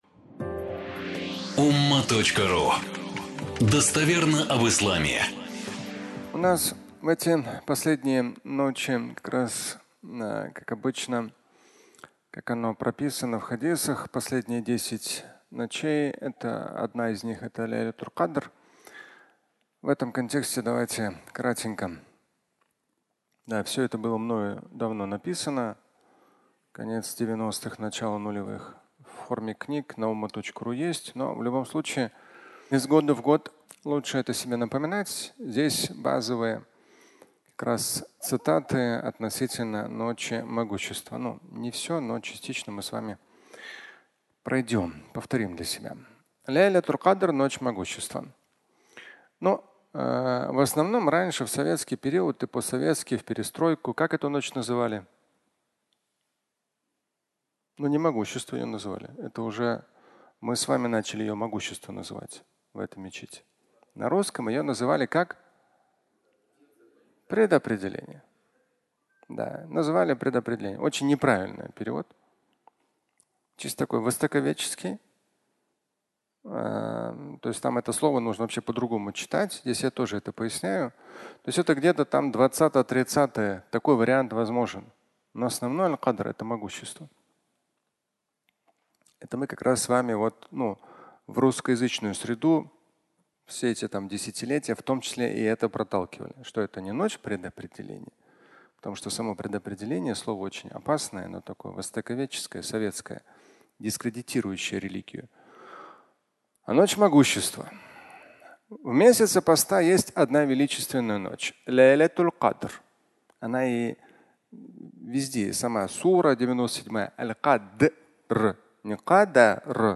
Ночь могущества (аудиолекция)
Фрагмент пятничной лекции